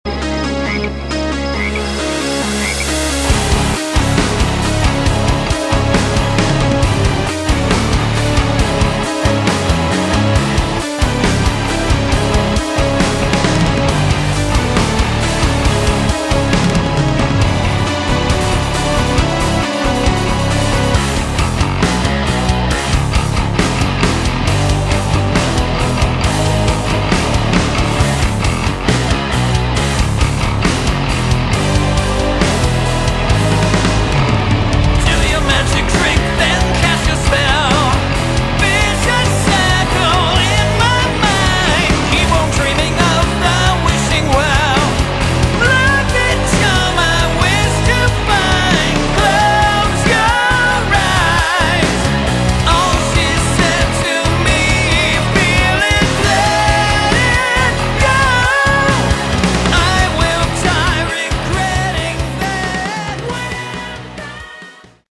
Category: Melodic Metal
lead and backing vocals
guitars, bass, keyboards
drums